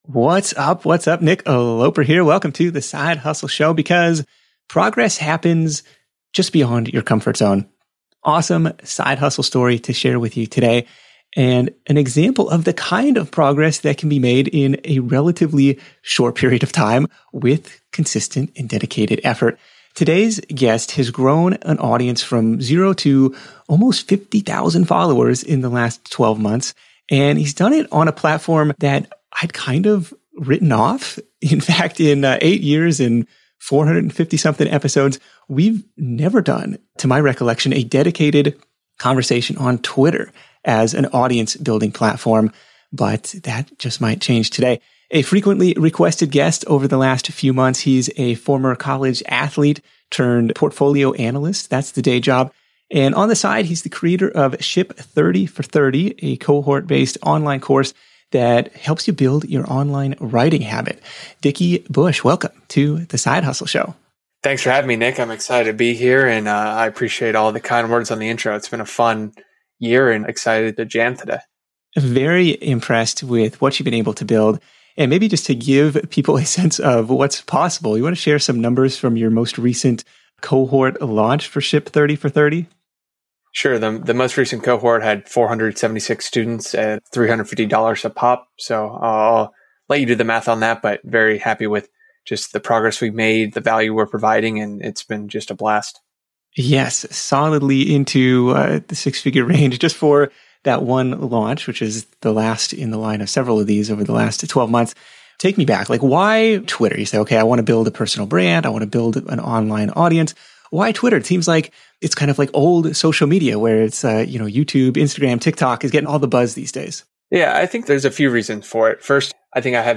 Tune in to The Side Hustle Show interview to learn: